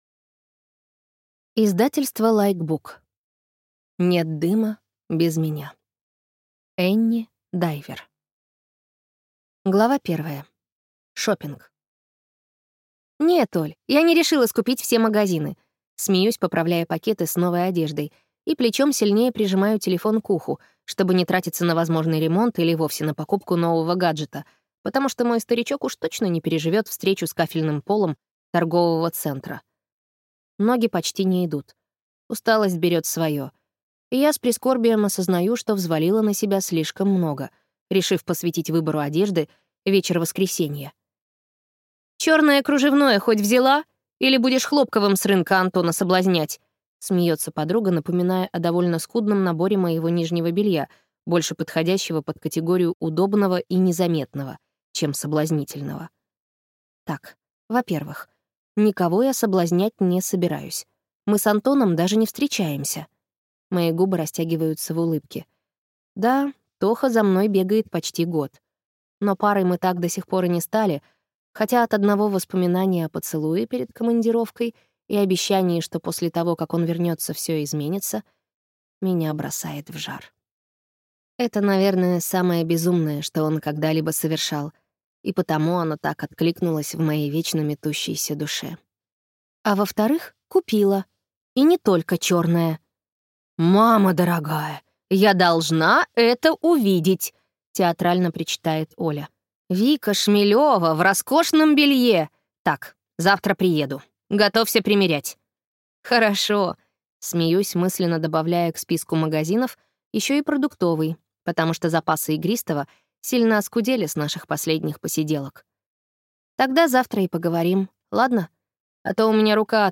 Аудиокнига Нет дыма без меня | Библиотека аудиокниг
Прослушать и бесплатно скачать фрагмент аудиокниги